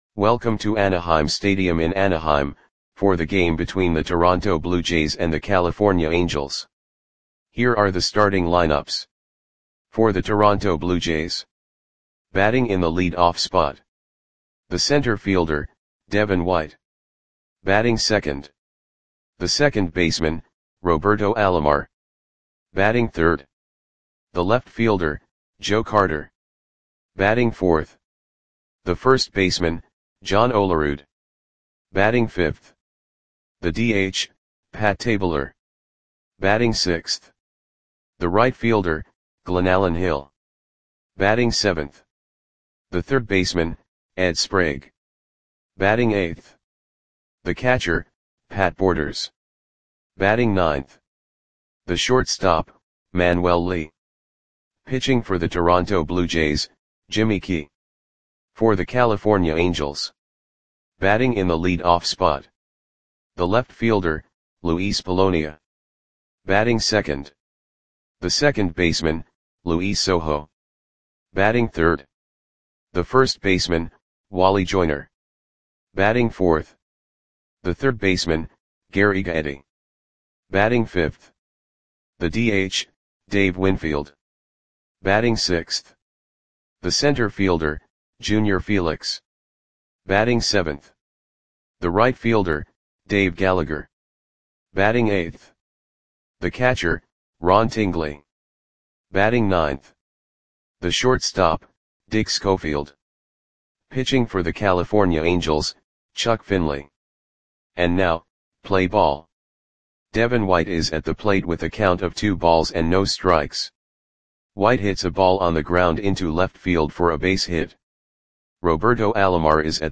Lineups for the California Angels versus Toronto Blue Jays baseball game on May 24, 1991 at Anaheim Stadium (Anaheim, CA).
Click the button below to listen to the audio play-by-play.